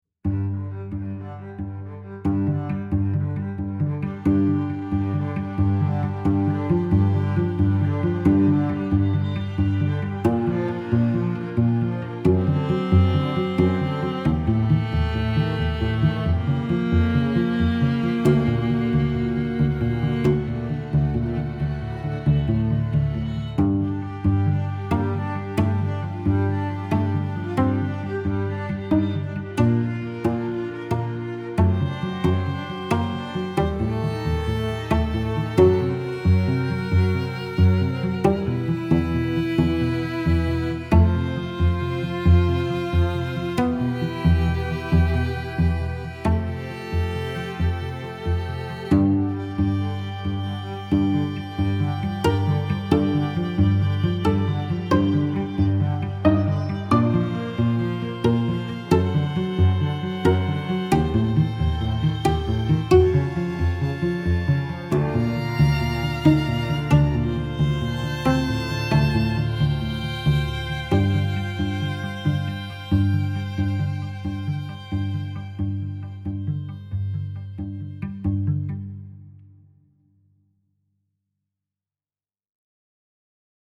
without percussion